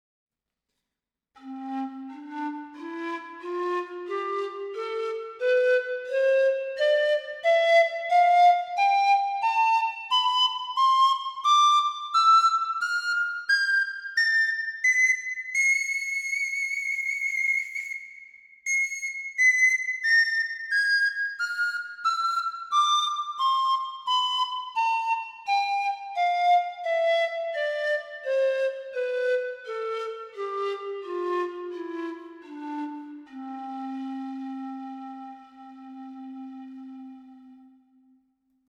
Пан-флейта Gibonus FPS-Tenor Cherry
Пан-флейта Gibonus FPS-Tenor Cherry Тональность: С
Диапазон - три октавы (С1-С4), строй диатонический.